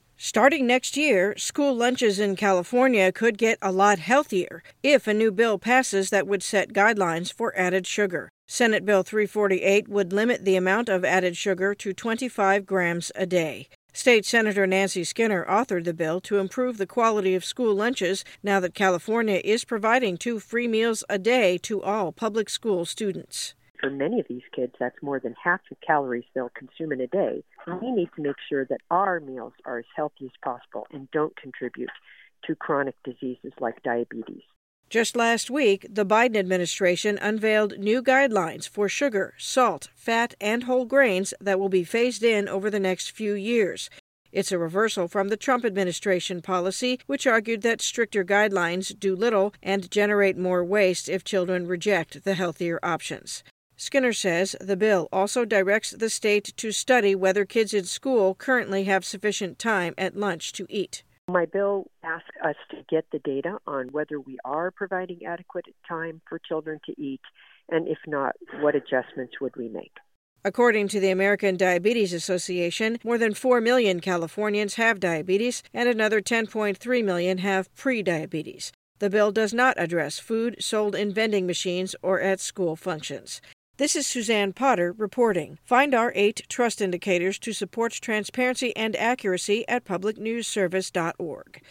Voiceovers